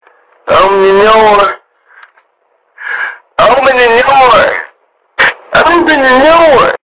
Open The Noor Loud